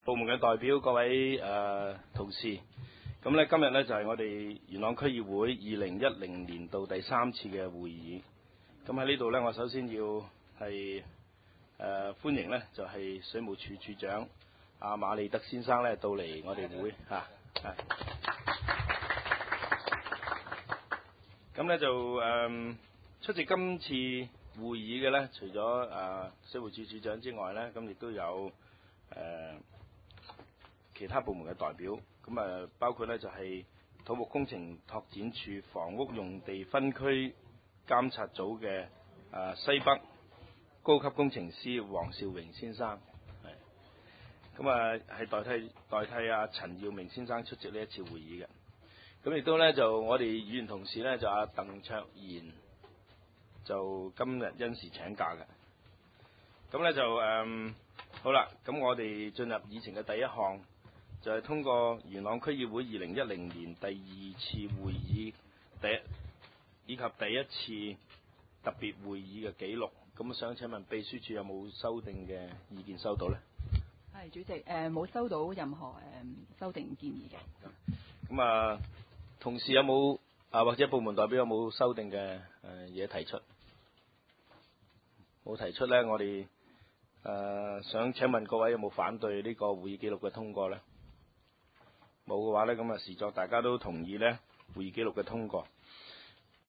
點：元朗橋樂坊二號元朗政府合署十三樓元朗區議會會議廳